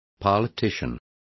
Complete with pronunciation of the translation of politician.